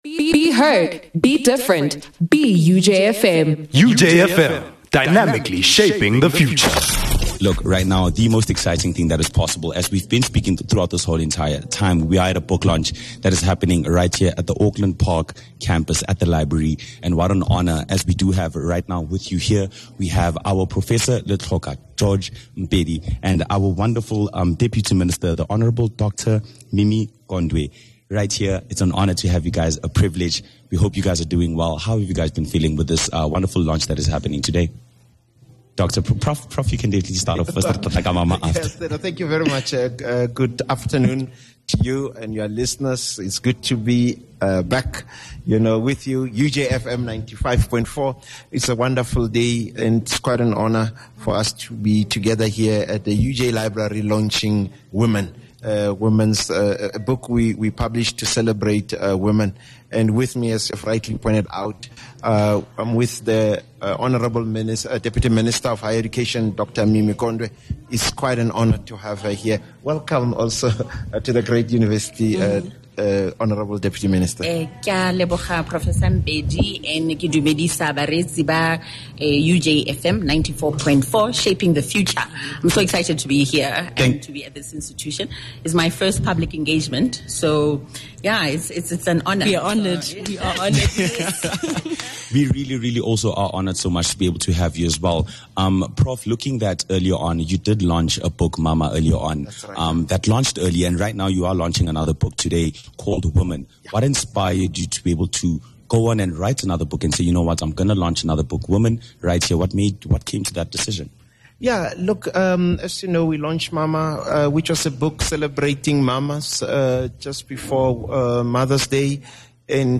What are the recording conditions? His insights were shared on the UJFM Lunch Break Show, highlighting the necessity of collective efforts in addressing gender inequalities.